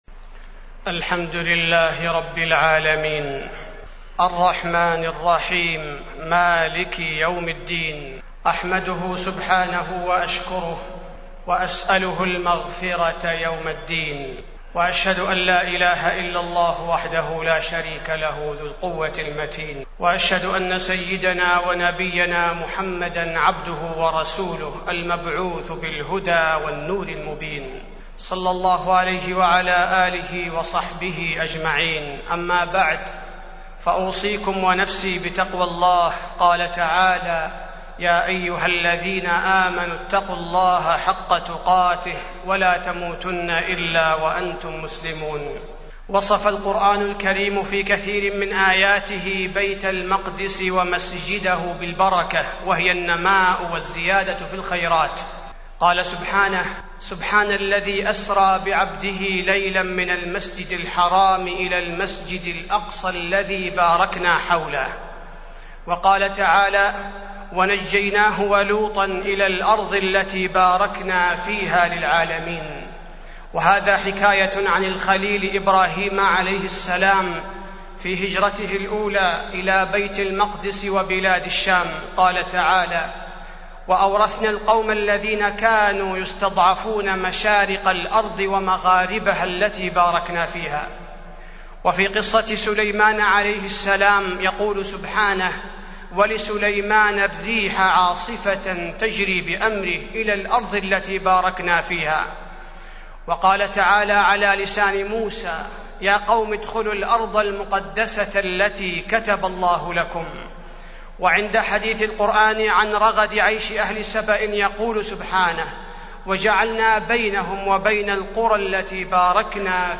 تاريخ النشر ٢١ محرم ١٤٢٨ هـ المكان: المسجد النبوي الشيخ: فضيلة الشيخ عبدالباري الثبيتي فضيلة الشيخ عبدالباري الثبيتي فضائل المسجد الأقصى